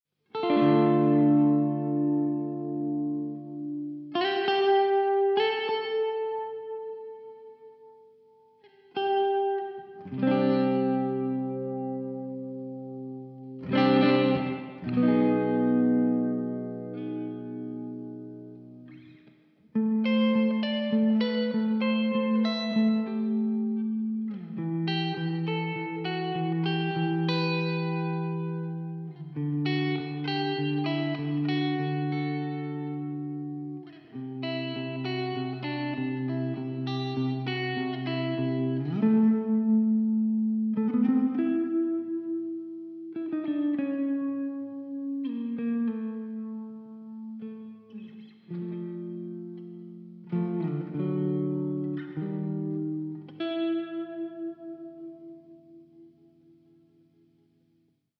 Aufgenommen wurden die folgenden Klangbeispiele an der Neck-Position einer Harley Benton CST-24. Der standardmäßig verbaute Tonabnehmer wurde durch einen Seymour Duncan SH-1 getauscht. Verstärkt wurde das Ganze mit einem Laney Ironheart Studio und einer emulierten Marshall 1960 Lead Box.
Alle Regler wurden hierfür auf die 12 Uhr Position gebracht.
Spring Reverb
Im Touch My Hall klingt dieser Reverb-Typ sehr weich und lädt zum Träumen ein.
tb_audio_touchmyhall_02_spring_12_uhr.mp3